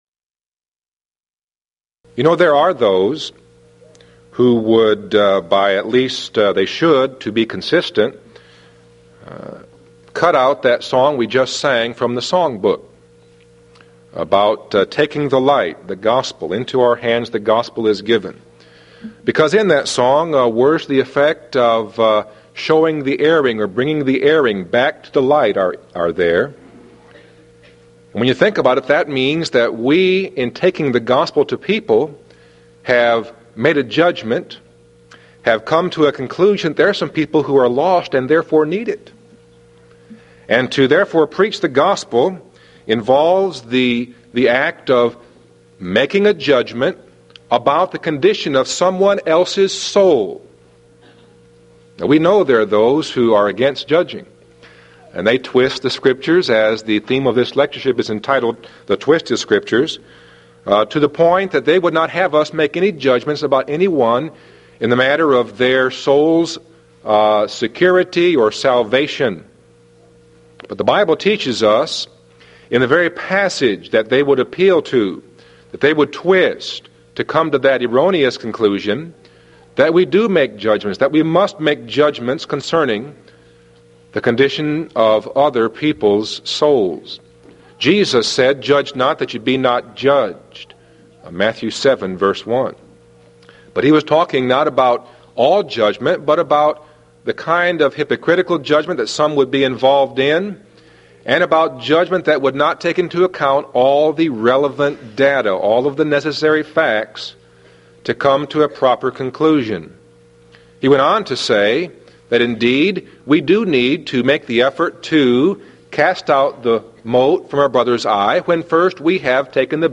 Event: 1995 Mid-West Lectures Theme/Title: The Twisted Scriptures
this lecture